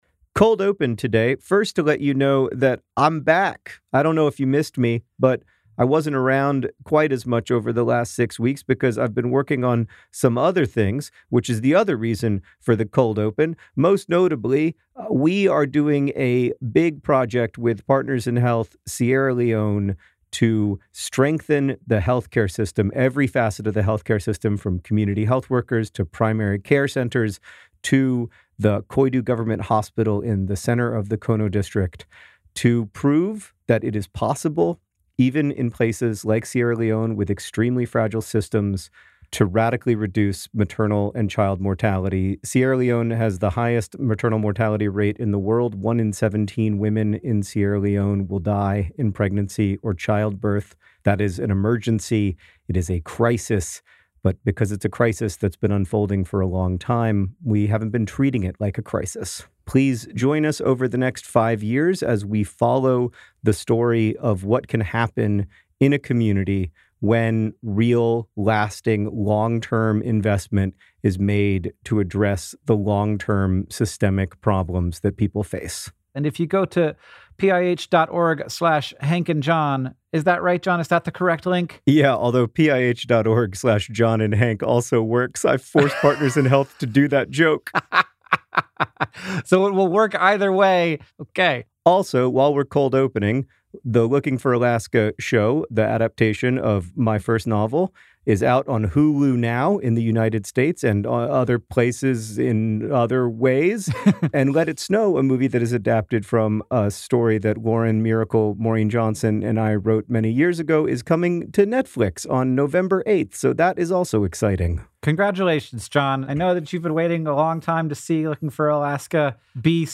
Is climate change for real? Hank Green and John Green answer your questions!